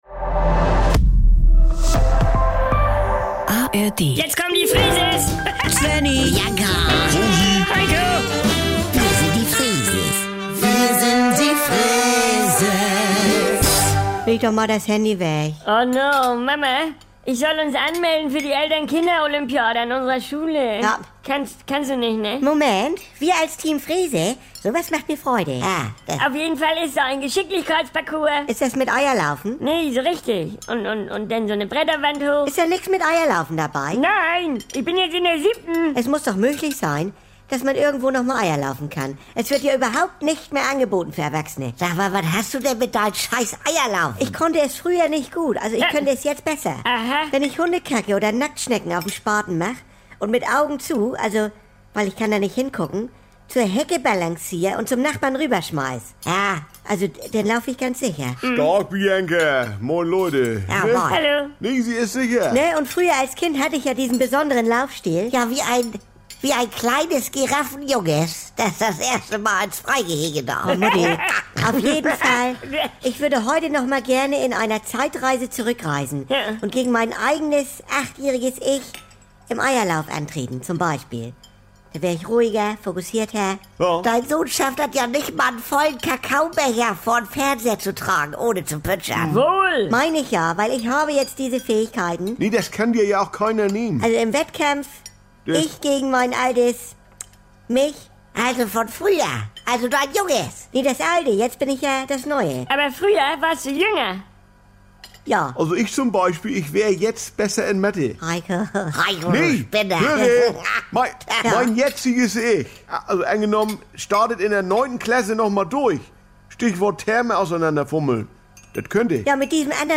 … continue reading 1871 эпизодов # Saubere Komödien # NDR 2 # Komödie # Unterhaltung